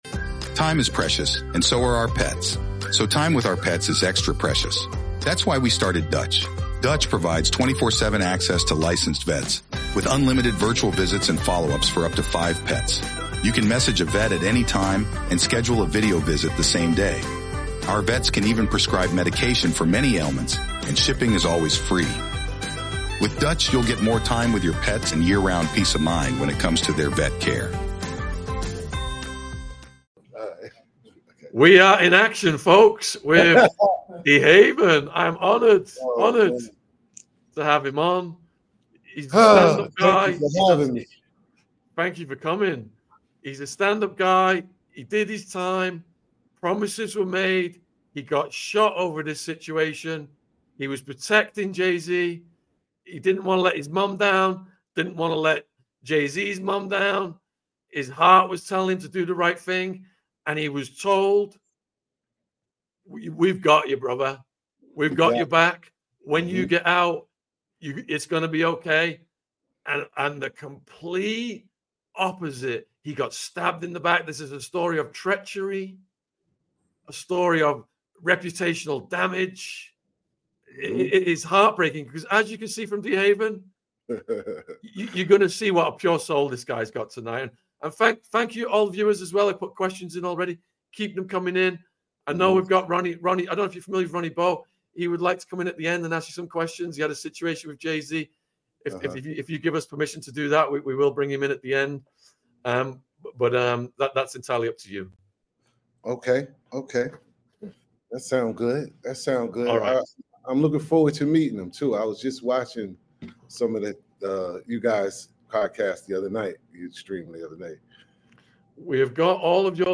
Join us for an in-depth livestream exploring the intricate dynamics of loyalty and betrayal within the unforgiving world of street life.